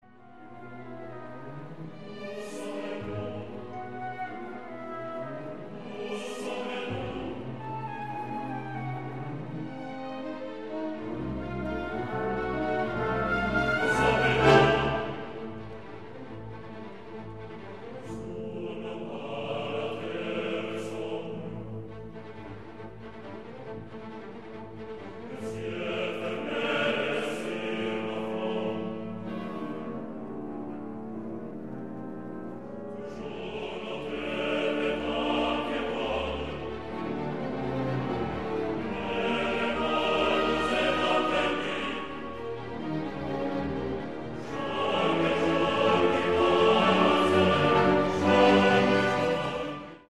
Oppositions chœurs terrestres / chœurs célestes, solistes … le matériau musical est le même, pour une intention religieuse similaire : Franck déclarait à propos du poème d’Edouard Blau : Je le mettrai en musique et le ferai bien, car ce qu’il y a là-dedans, je le crois ; à rapprocher de sa déclaration à propos du poème de Mme Colomb : Ce qu’il y a là-dedans, j’y crois.